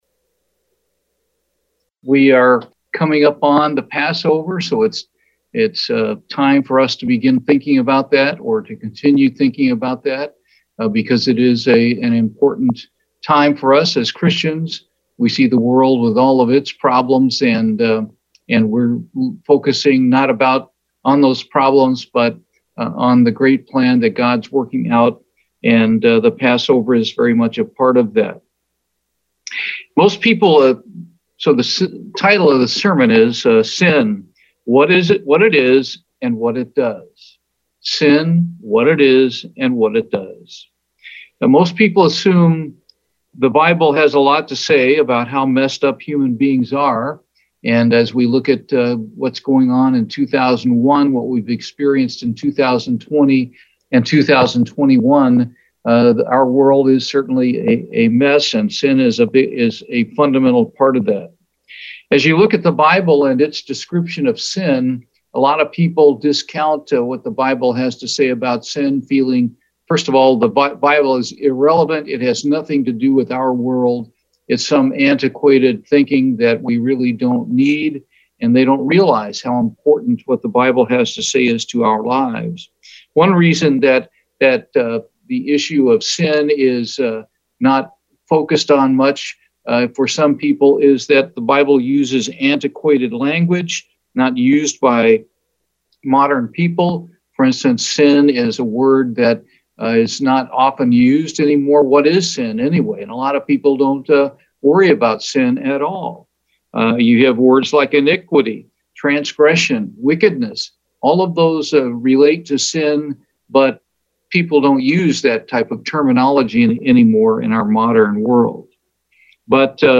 Sermons
Given in Houston, TX